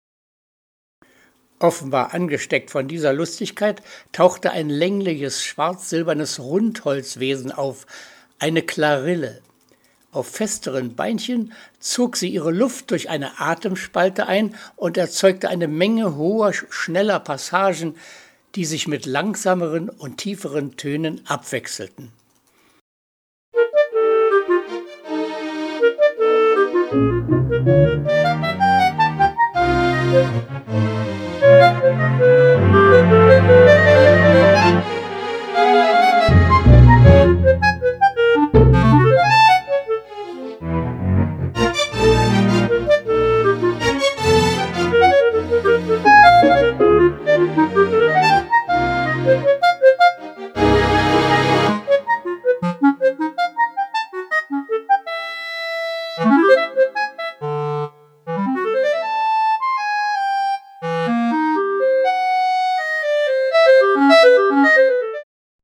Sinfonische Dichtung